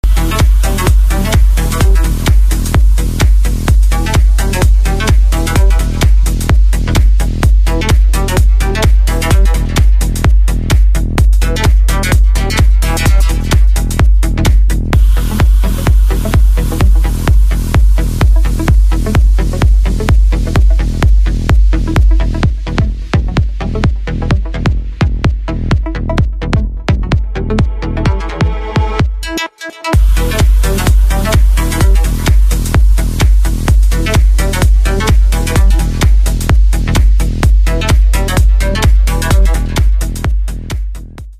• Качество: 128, Stereo
Electronic
post-bro